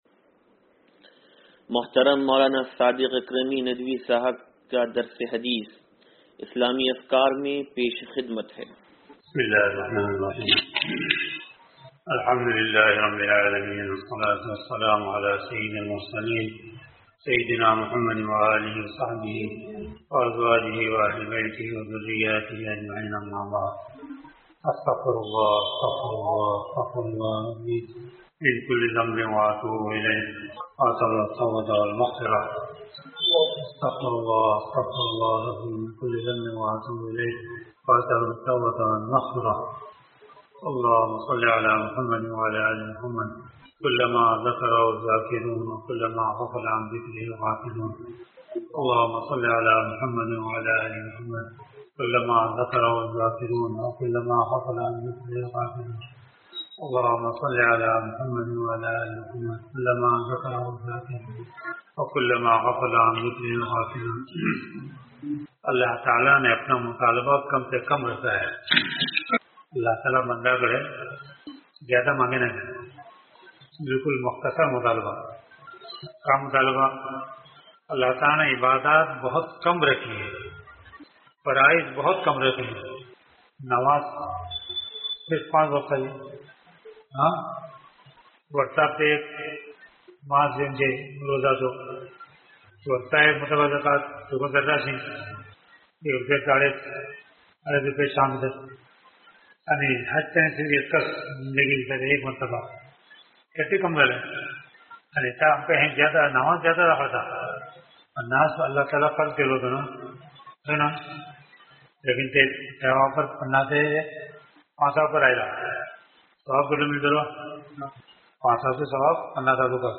درس حدیث نمبر 0472